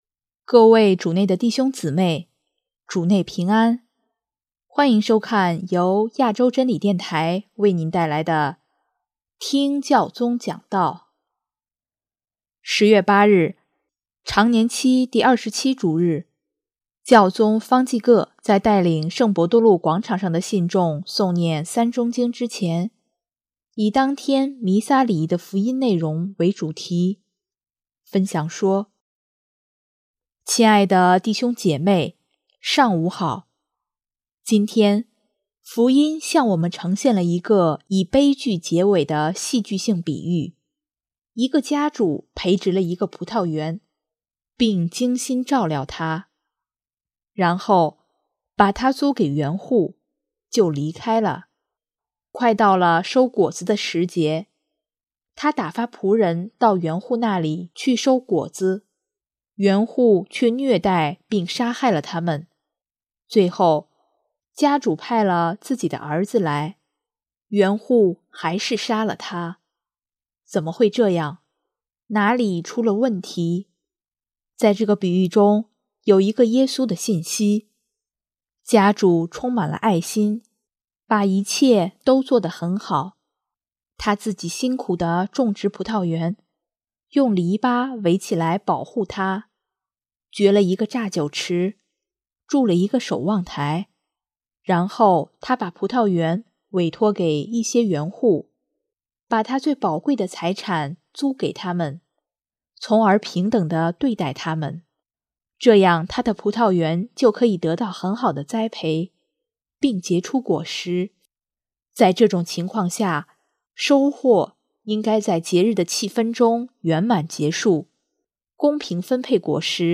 【听教宗讲道】|我是否意识到生命中的礼物？
10月8日，常年期第二十七主日，教宗方济各在带领圣伯多禄广场上的信众诵念《三钟经》之前，以当天弥撒礼仪的福音内容为主题，分享说：